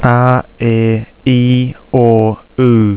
Like English, there are five vowels: a, e, i, o, u. These are pronounced to rhyme with "are there three or two": "arr eeh ee or oou".
aeiou.wav